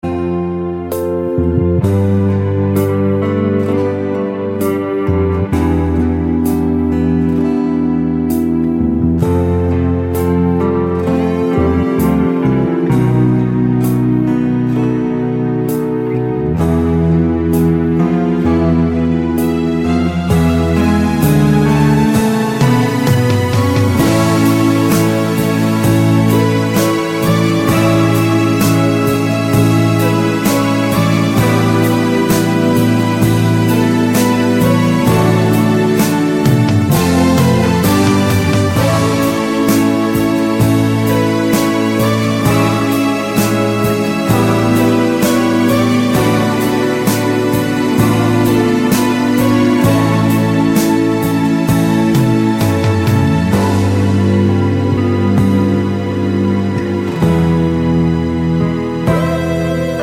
Medleys